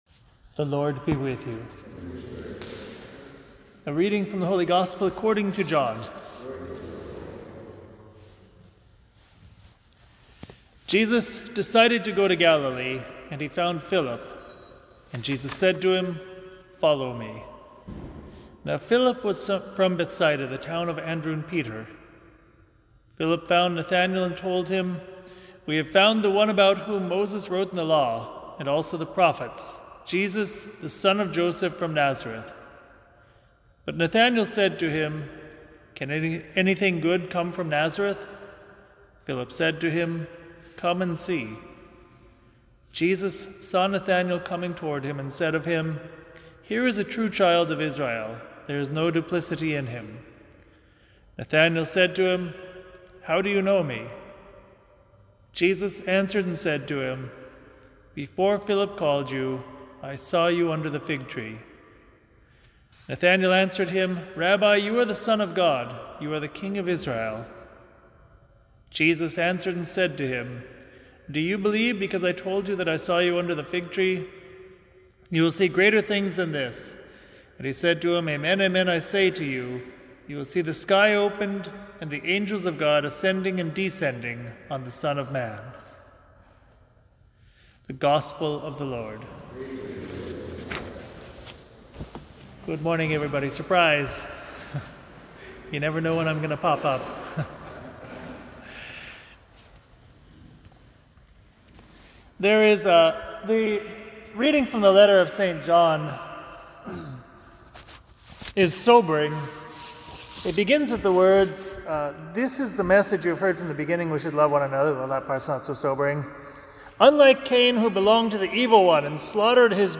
Listen to the Family Friday Homily